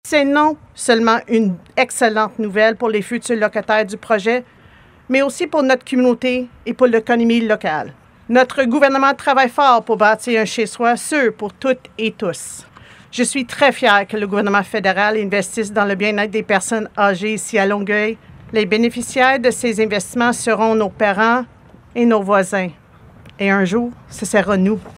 La députée de Longueuil-Charles-Lemoyne, Sherry Romanado soutient que cette aide aux aînés est primordiale.